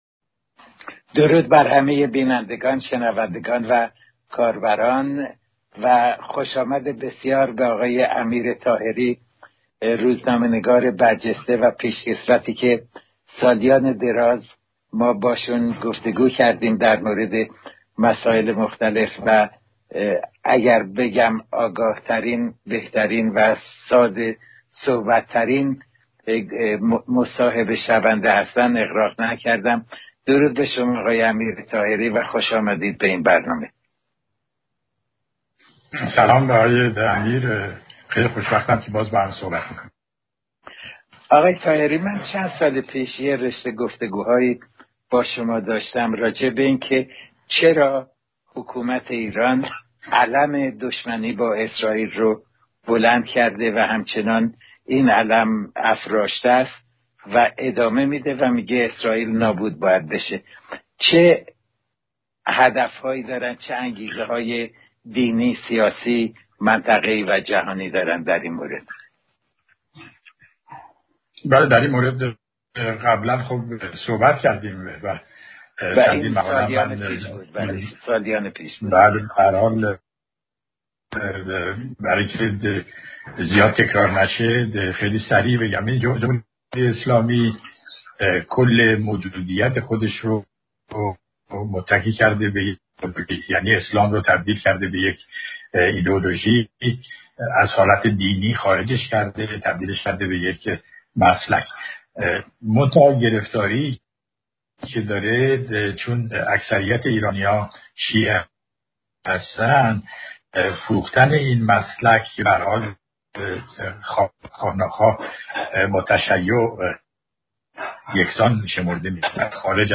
پادکست مصاحبه بسیار شنیدنی دو ابرژورنالیست ایرانی، راجع به دشمنی رژیم نکبت با دولت اسرائیل و جایگاه سپاه پاسداران